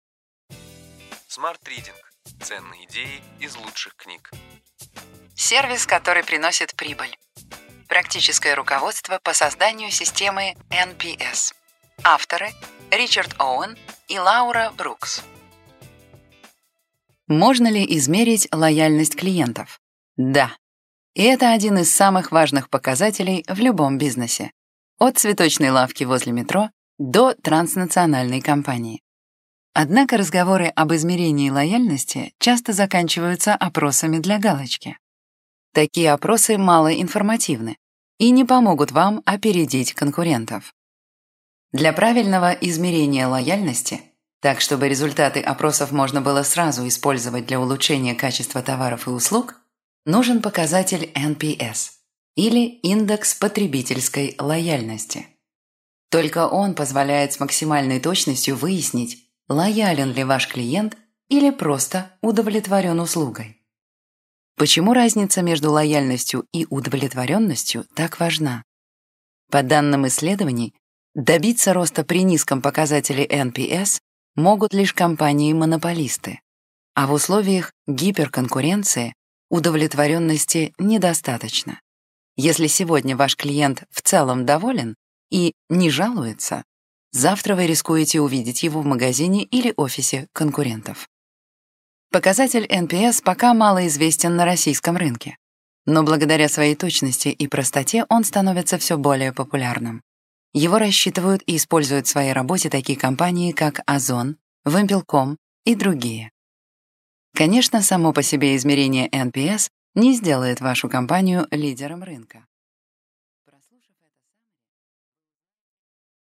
Аудиокнига Ключевые идеи книги: Сервис, который приносит прибыль.